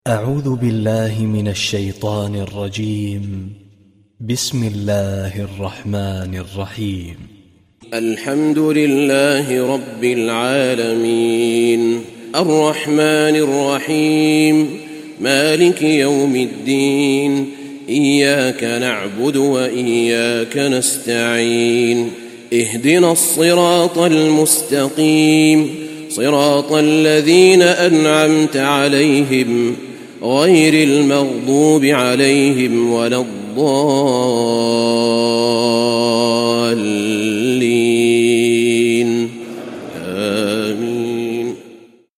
Surah Fatiha Recitation